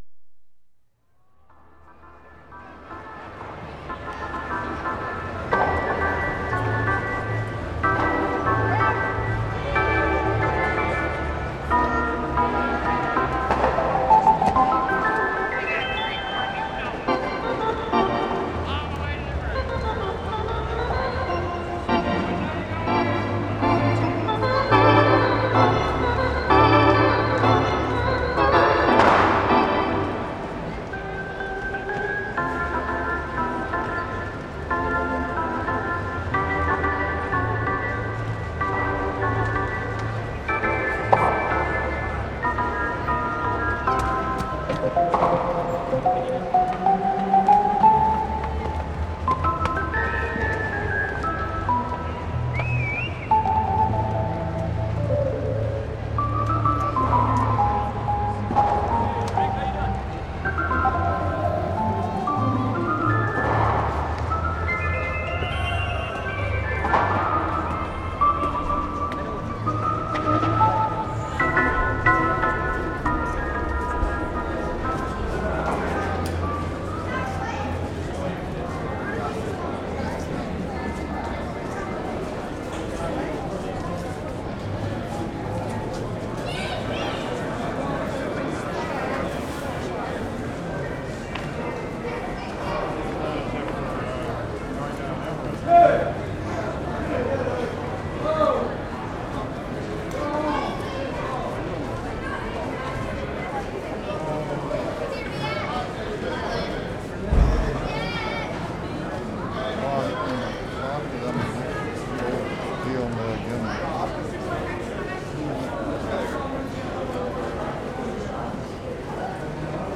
TORONTO, ONTARIO Nov. 3, 1973
AFTER GAME: FOYER AMBIENCE 4'10"
2. Recordists walking out of rink into foyer/escalator area. Starts with loud organ moozak in very reverberant space. Nice ambience change into foyer filled with a crowd (1'15"). No more moozak. Movement through crowd is difficult to hear. Crowd is getting denser toward end of take.